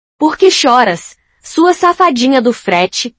porque choras safadinh4 Meme Sound Effect